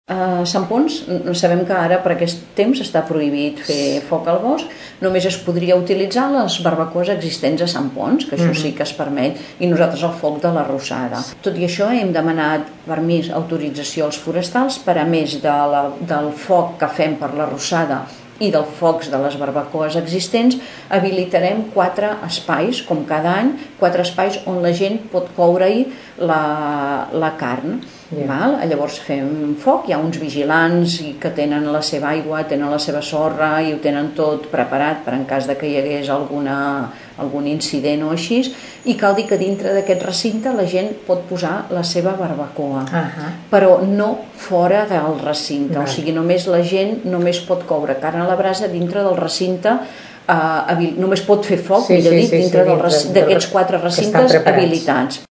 En dona detalls la regidora de festes, Maria Àngels Cayró.